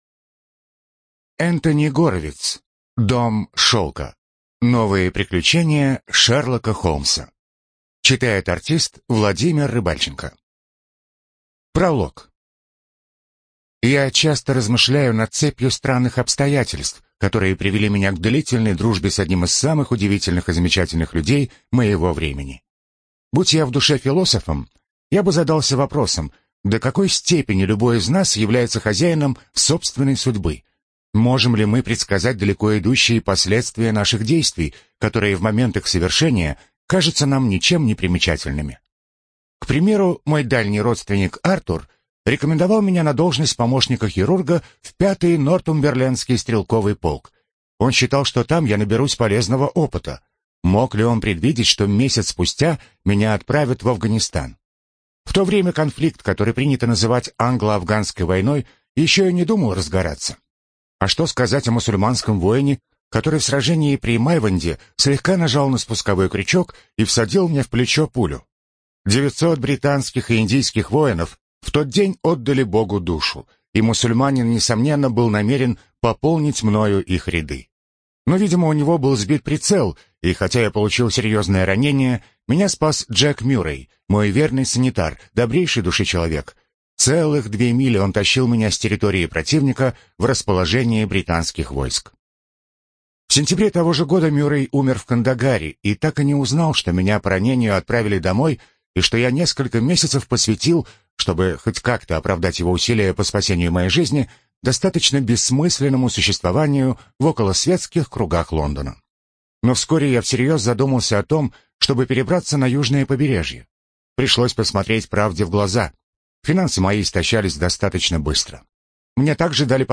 Жанр: Зарубежный детектив